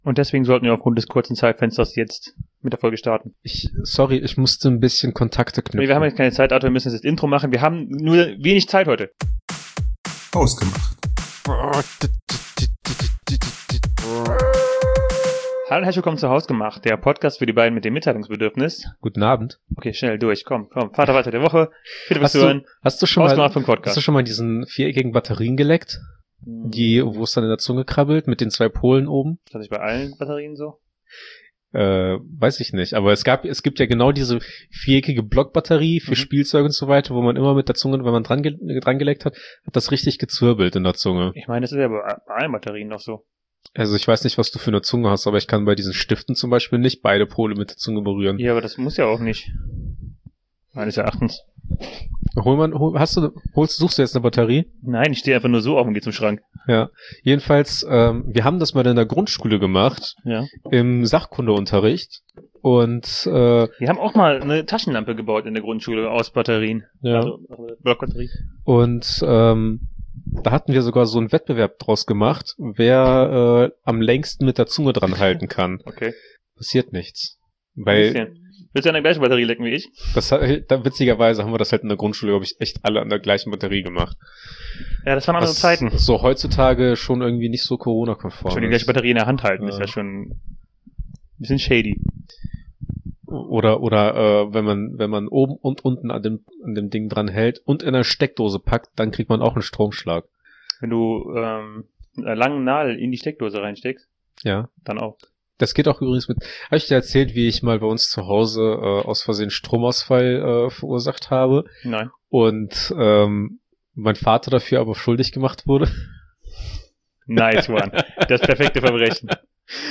Ausgestattet mit zwei Mikros zerpflücken die beiden sich selbst und ihre Leben, ernste Themen und Poltergeister, und alles was den Jungs sonst so durch den Kopf schießt. Kindischer Humor und dreckiger Sarkasmus erwarten euch jeden Dienstag!